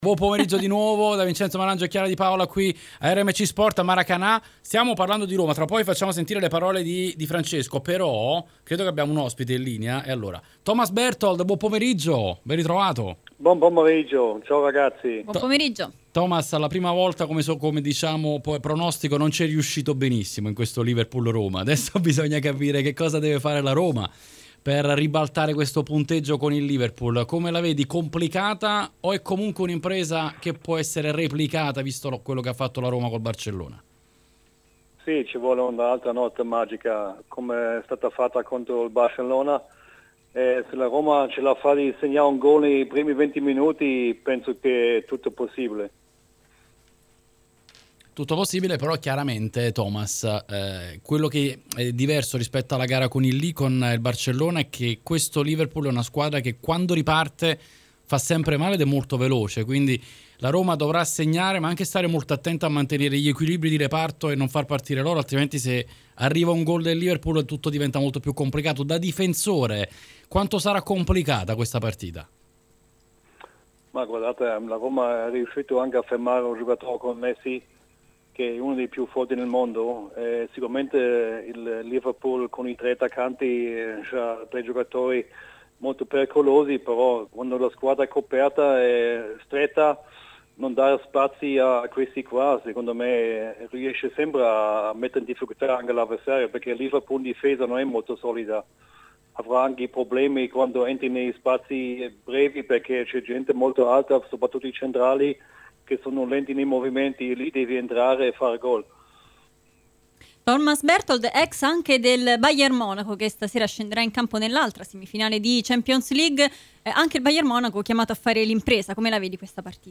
Thomas Berthold intervistato